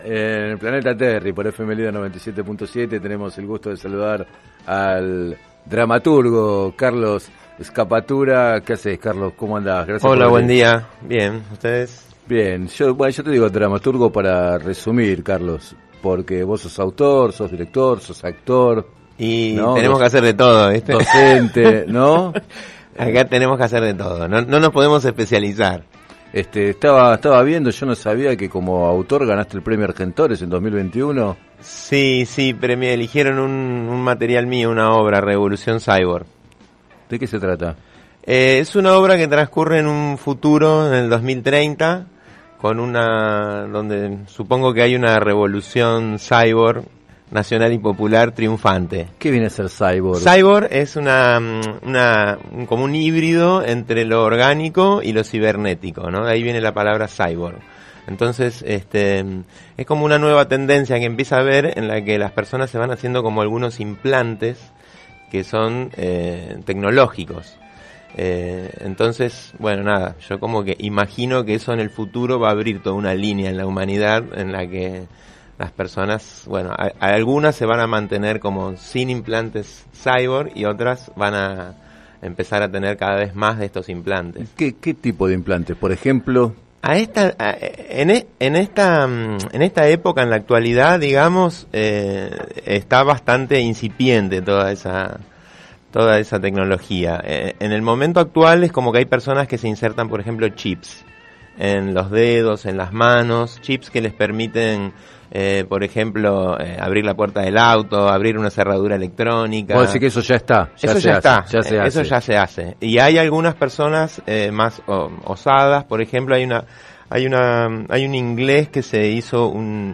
En declaraciones al programa “Planeta Terri” de FM Líder 97.7,